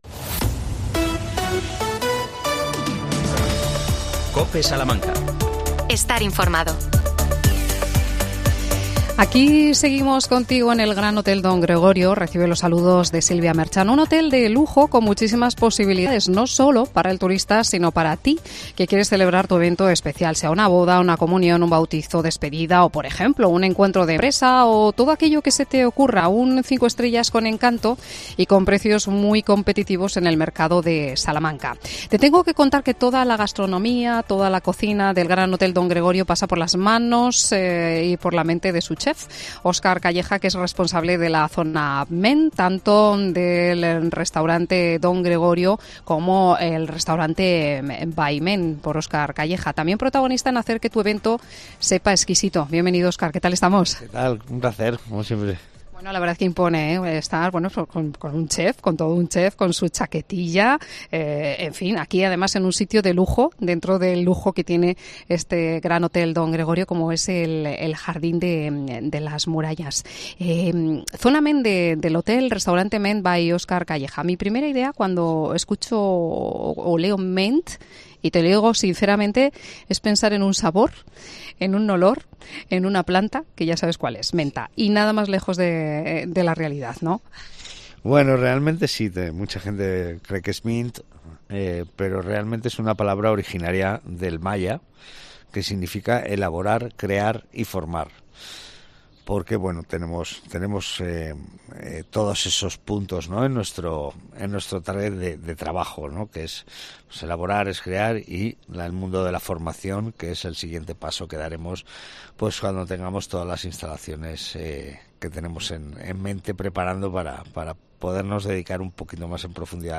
AUDIO: Hoy desde el Gran Hotel Don Gregorio.